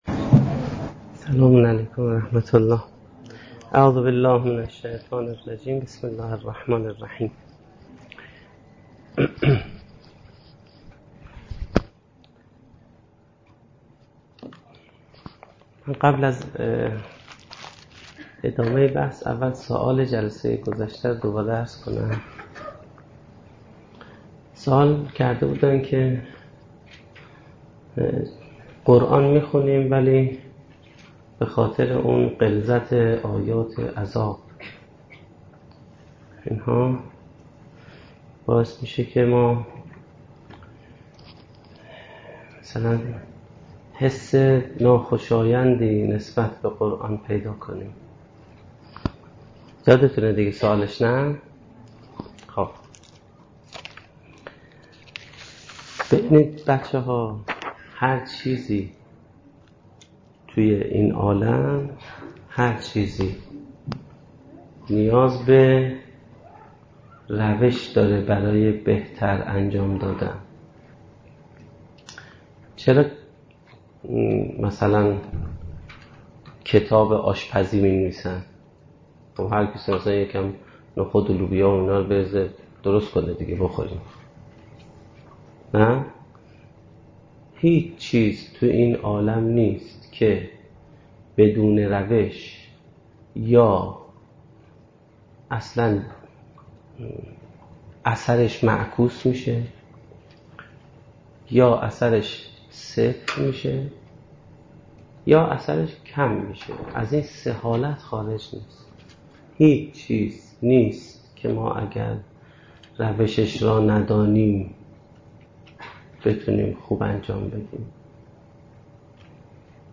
سخنرانی
در دانشگاه فردوسی